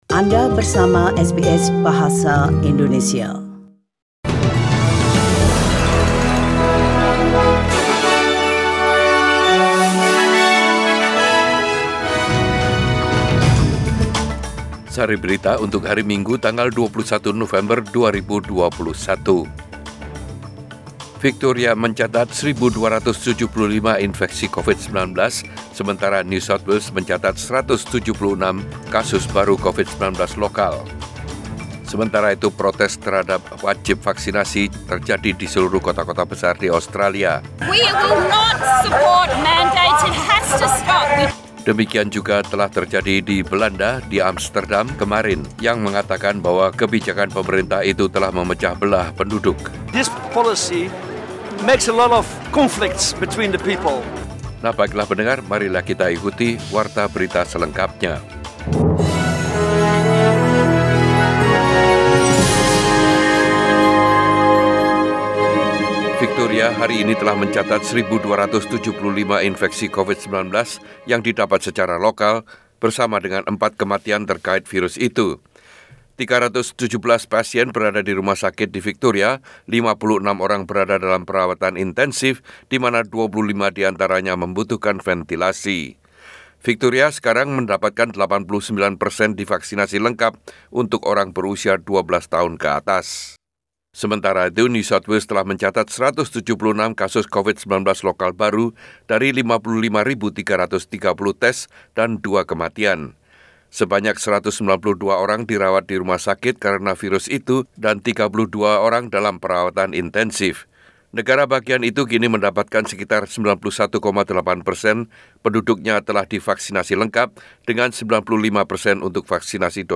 SBS Radio News in Bahasa Indonesia - 21 November 2021
Warta Berita Radio SBS Program Bahasa Indonesia.